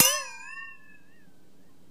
ding6
bell clang ding flanger gong metal metallic ting sound effect free sound royalty free Sound Effects